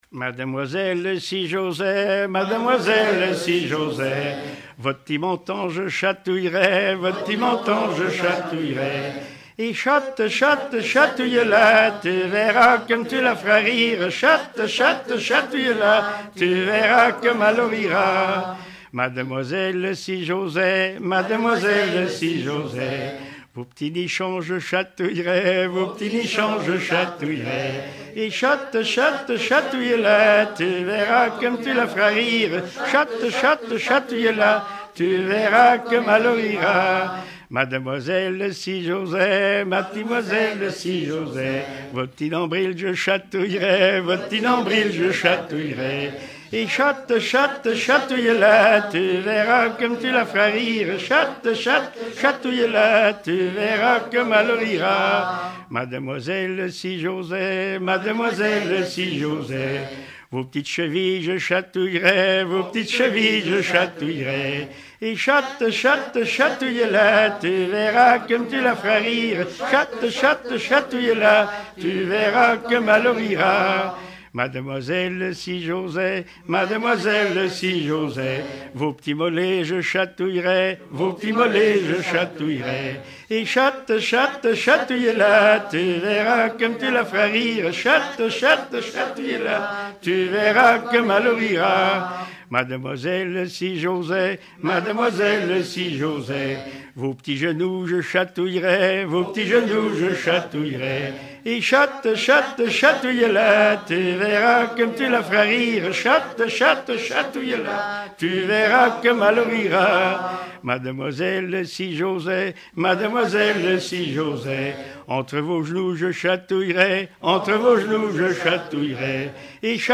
Genre énumérative
Regroupement de chanteurs du canton
Pièce musicale inédite